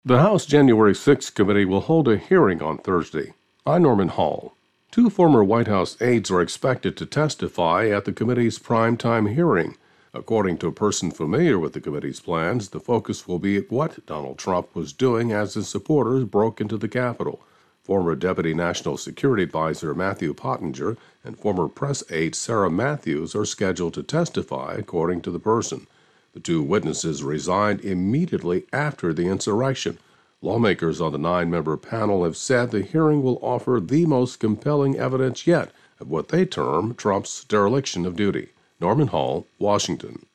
reports on Capitol Riot Investigation.